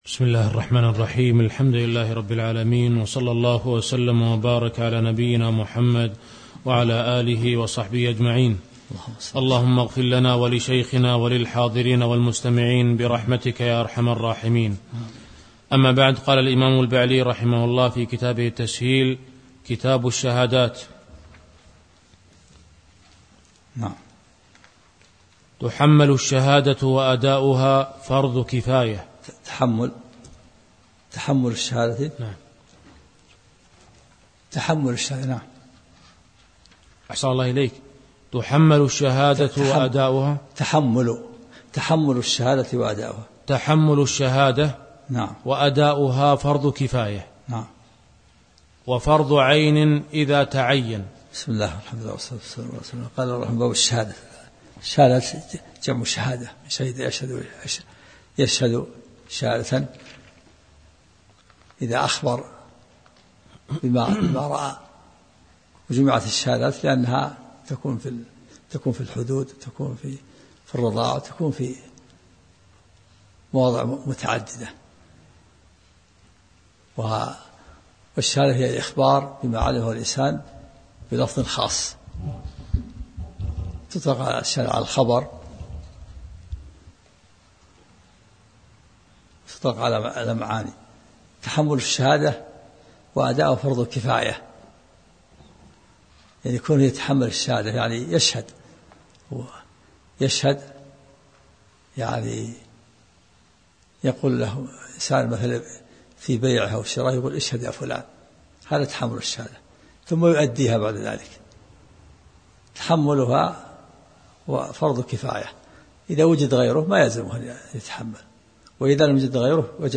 محاضرة صوتية نافعة